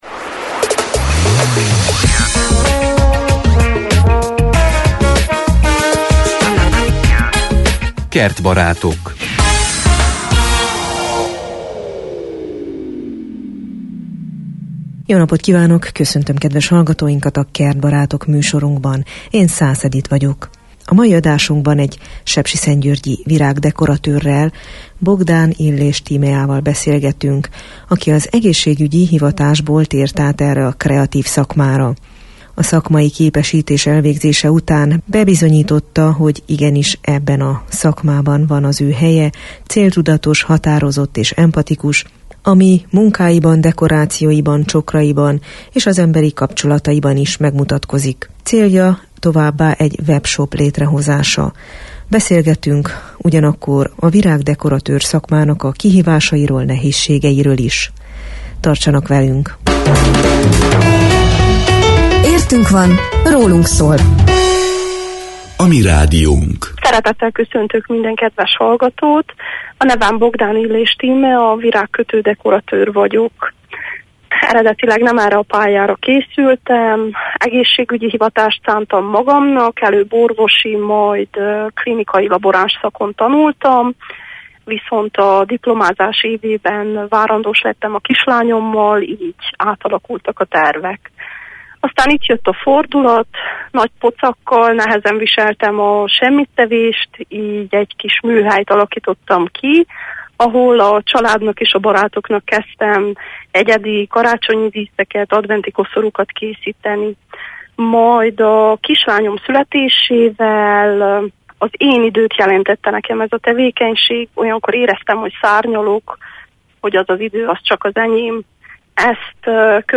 Kiderül az alábbi beszélgetésből.